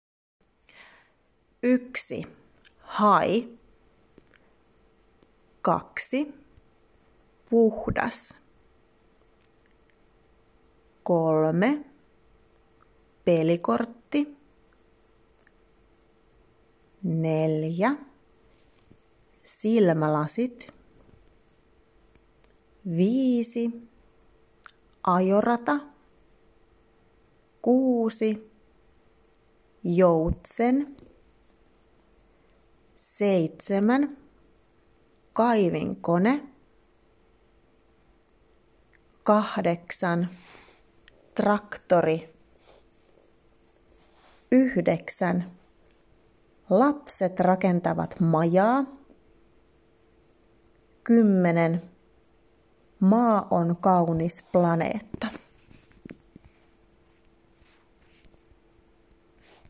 TEHTÄVÄ 2 (Sanelu)